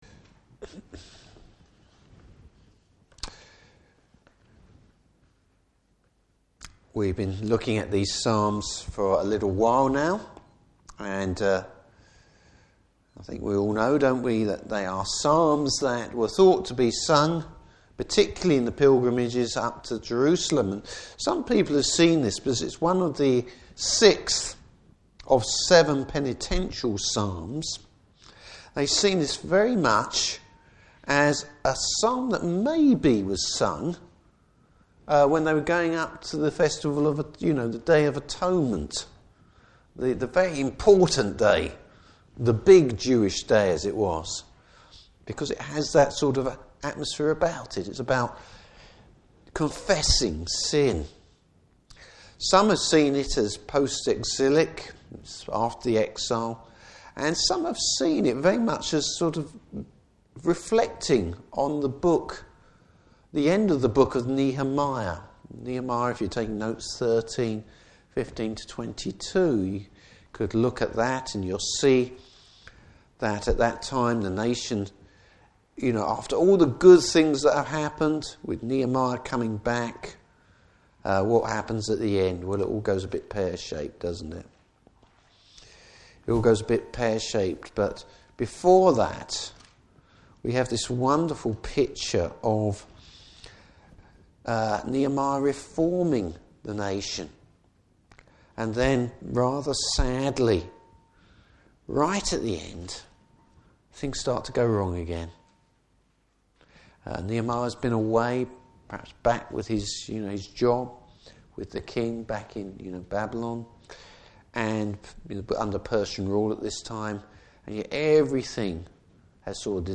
Service Type: Evening Service Repentance, Patience and confidence in God.